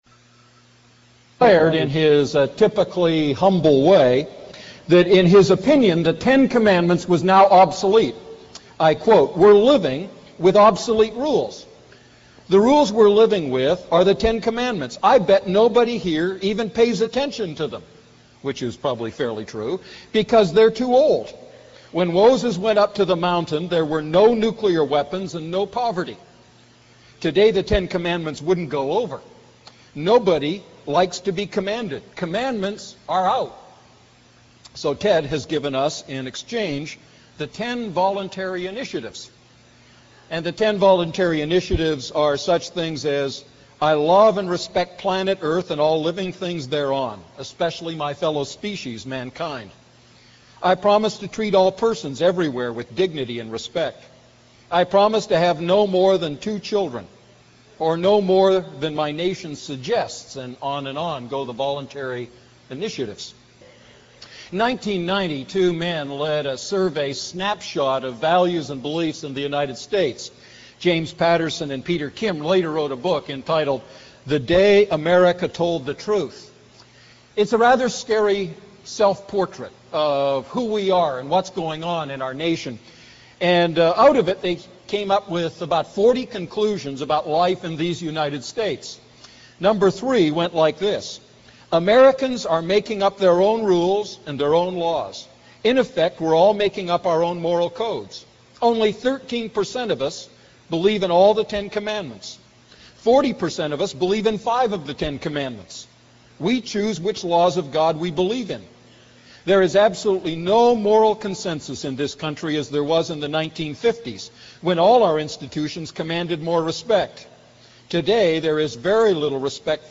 A message from the series "The Ten Commandments."